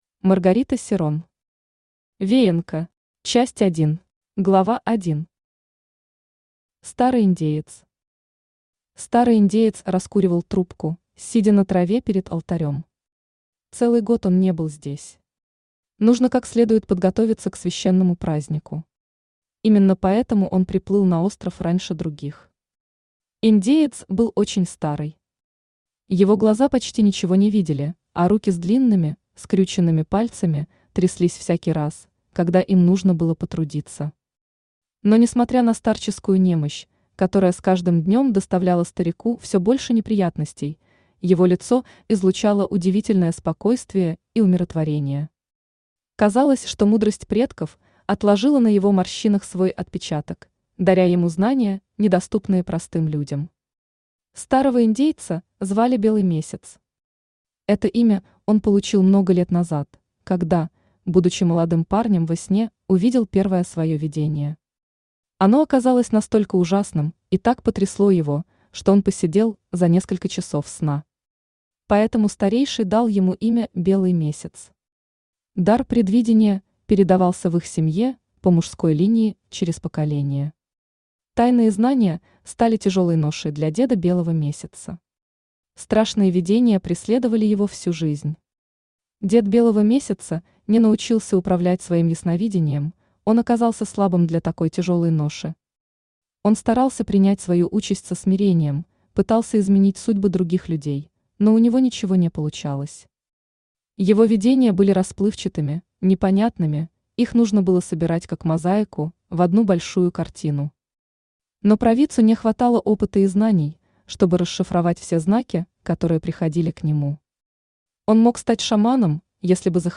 Аудиокнига Веянка. Часть 1 | Библиотека аудиокниг
Часть 1 Автор Маргарита Серрон Читает аудиокнигу Авточтец ЛитРес.